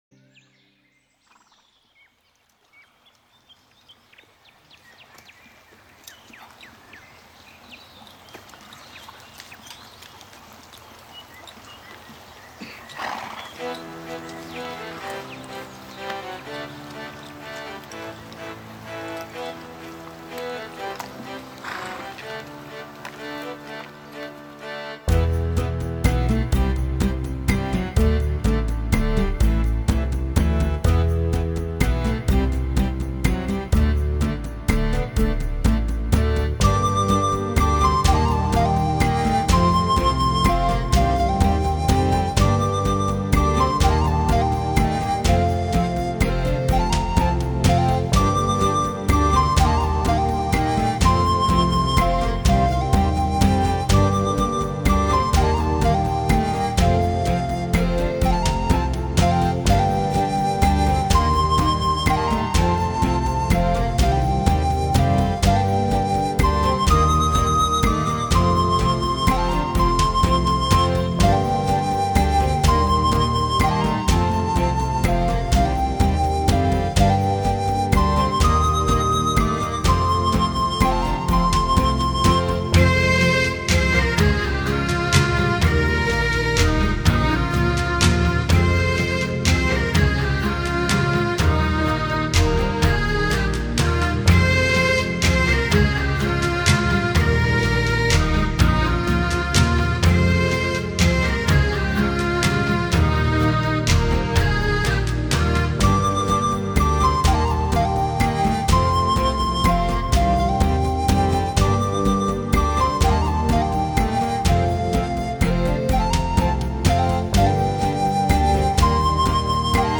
凯尔特音乐